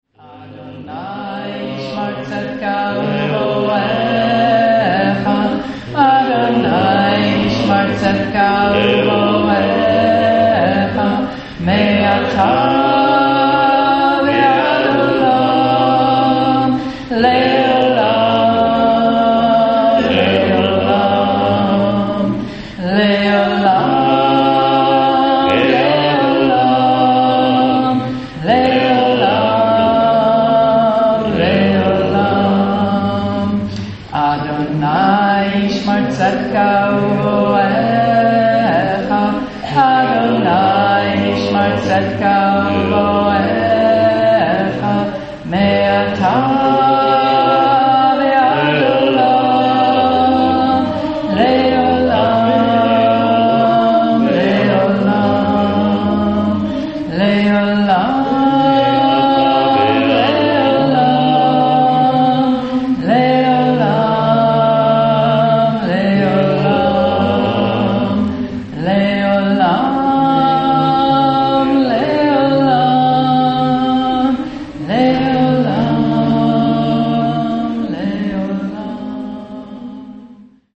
(Recorded in Danville, California 3/6/2009
Sacred Hebrew Chant